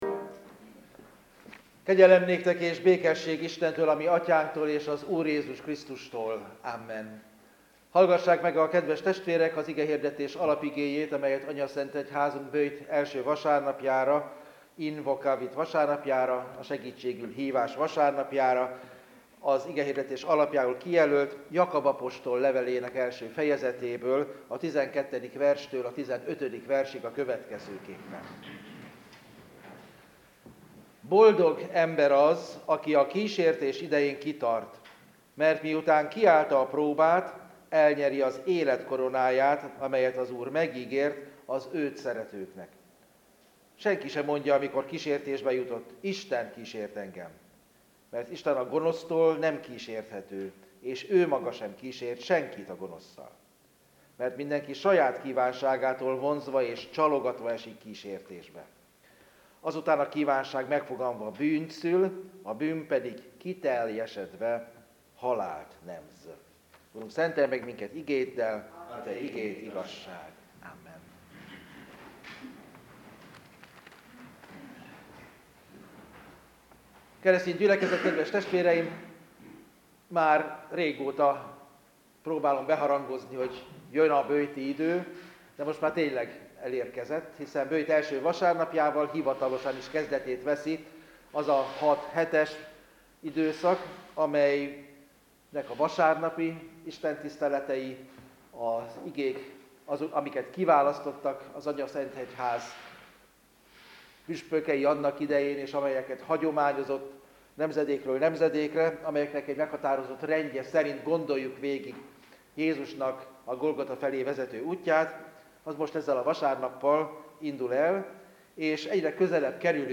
A Böjt 1. vasárnapi igehirdetés hanganyaga